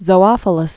(zō-ŏfə-ləs)